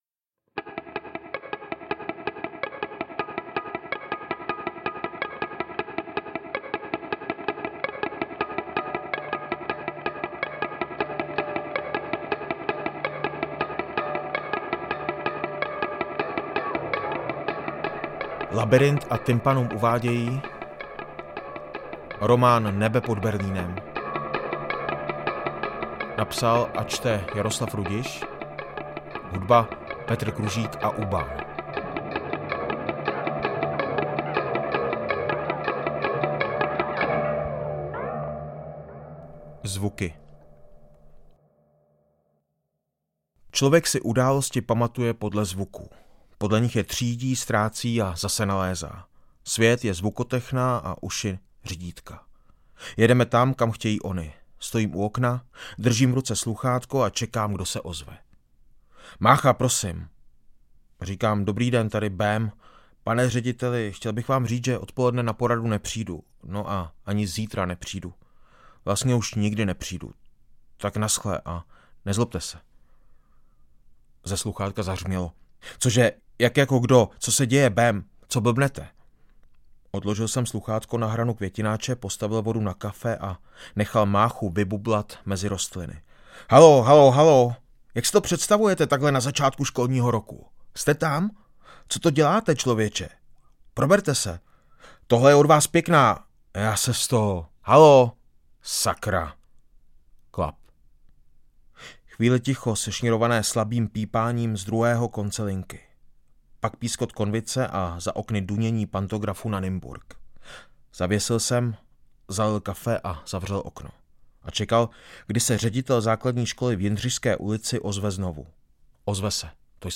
Audiobook
Read: Jaroslav Rudiš